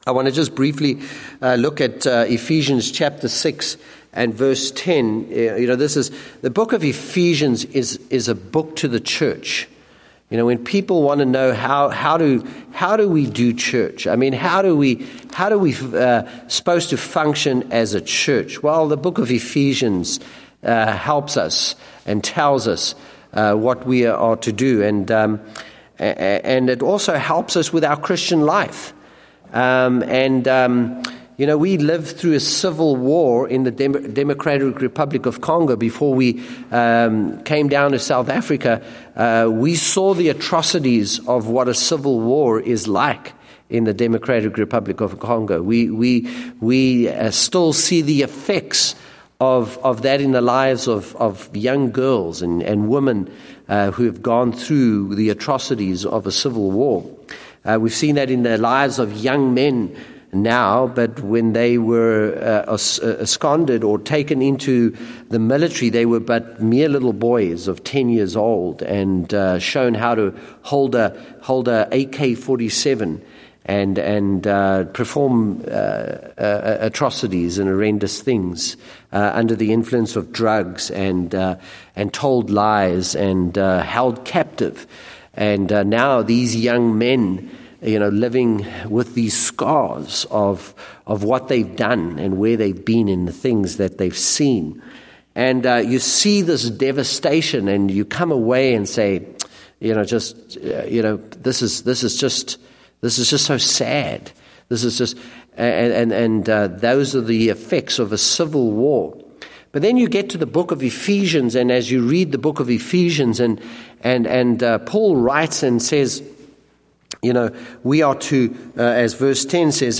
Date: September 14, 2014 (Evening Service)